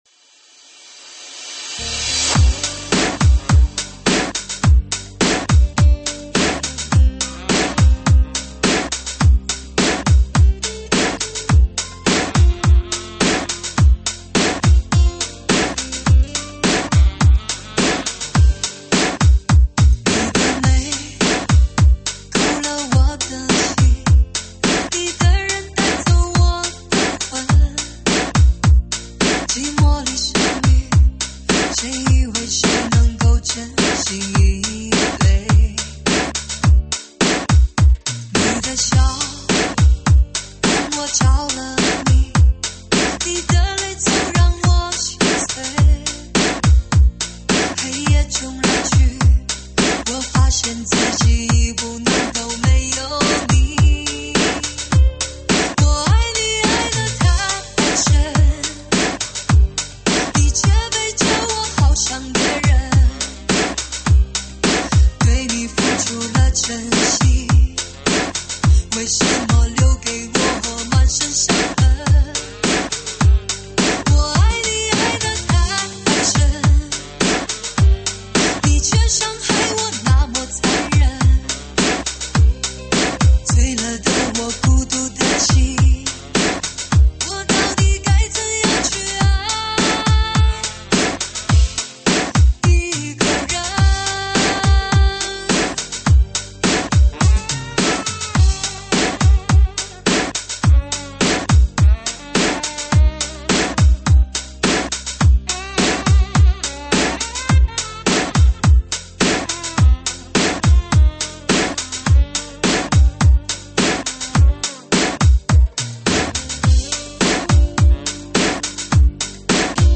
慢摇舞曲
舞曲类别：慢摇舞曲